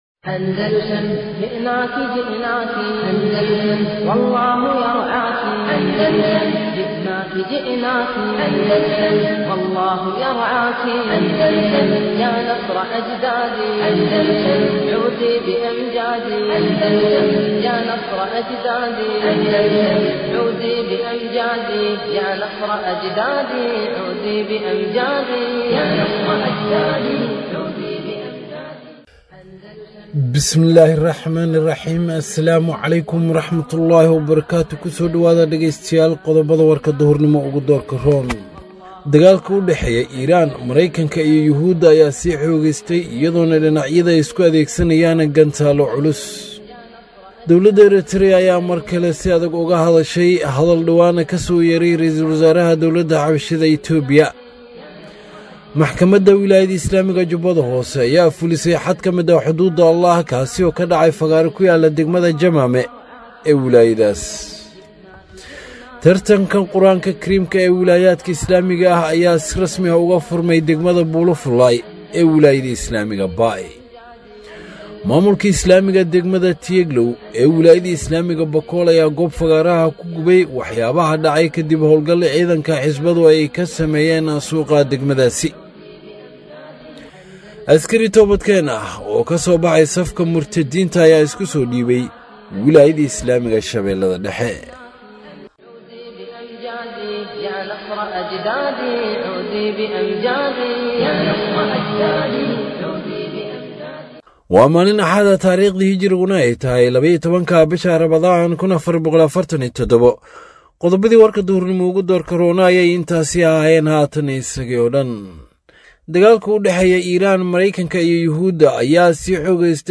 Warka Duhurnimo waa mid kamid ah wararka ugu muhiimsan ee ka baxa Idaacadda Al-andalus.